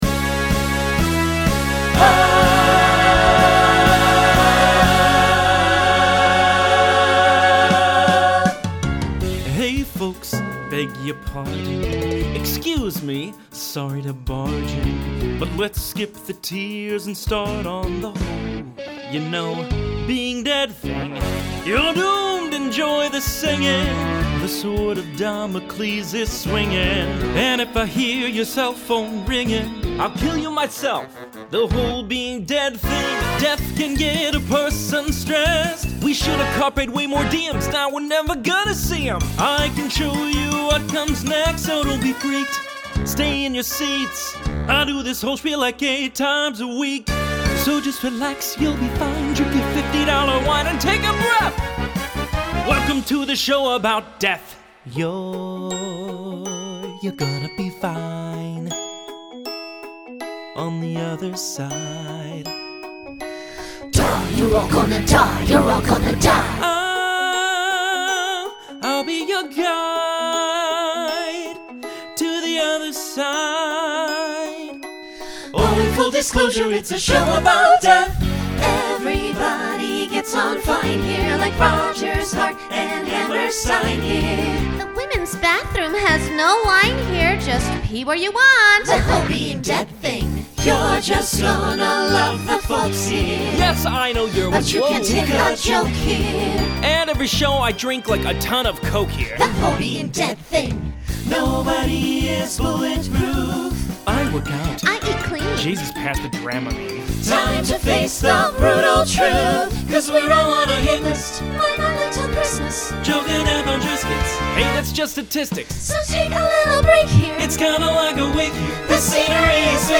Genre Broadway/Film Instrumental combo
Story/Theme Voicing SATB